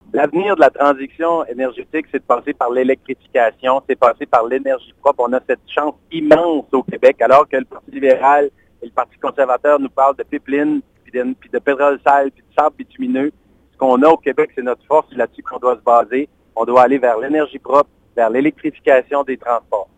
D’ici le scrutin, la salle des nouvelles de CFIM vous présente des entrevues thématiques réalisées chaque semaine avec les candidat(e)s. Les sujets abordés sont les suivants: Environnement (31 mars au 2 avril), Territoire et insularité (7 au 9 avril) et Perspectives économiques (14 au 16 avril).